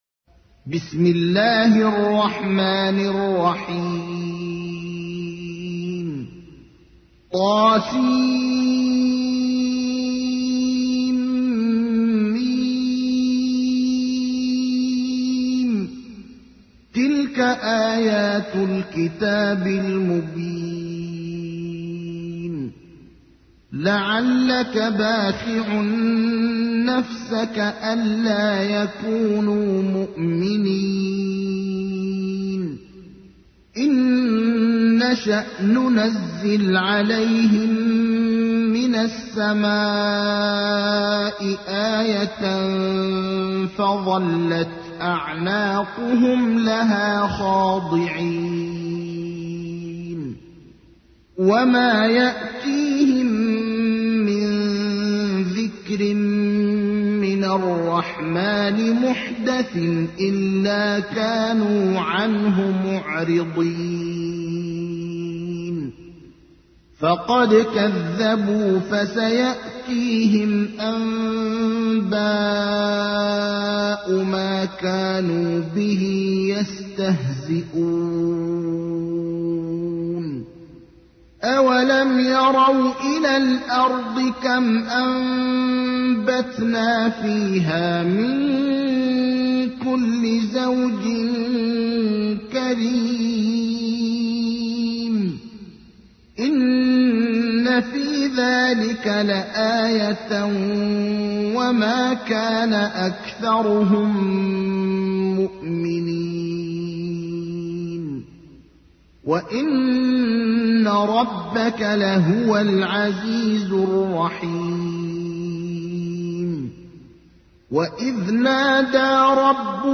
تحميل : 26. سورة الشعراء / القارئ ابراهيم الأخضر / القرآن الكريم / موقع يا حسين